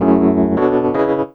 12CHORD01 -R.wav